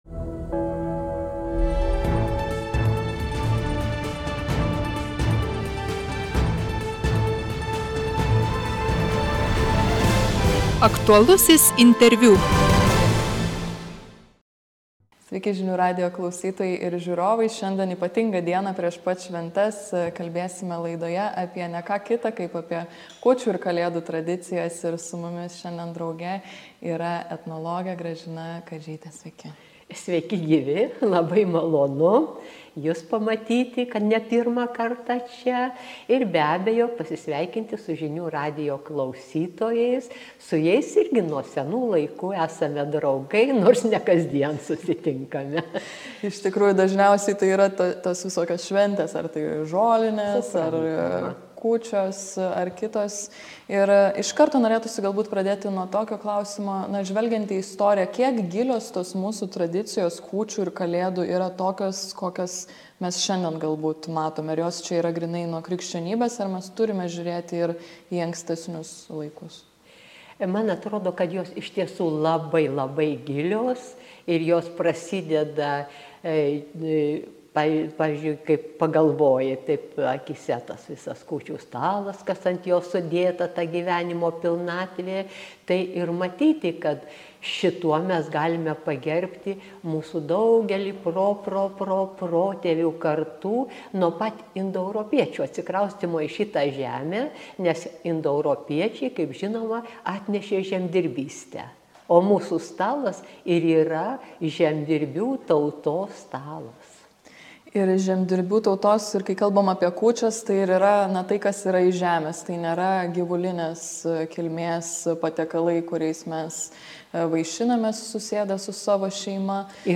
Aktualusis interviu